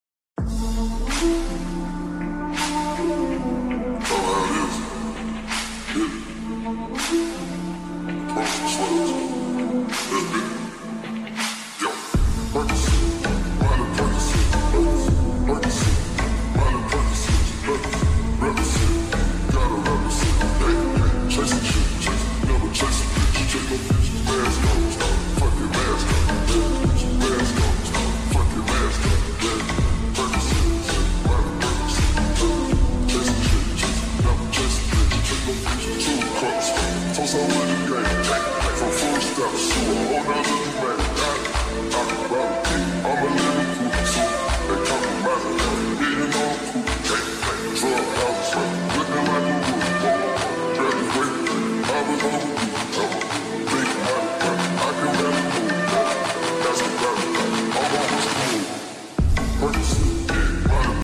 BEST SUPER ULTRA SLOWED MUSIC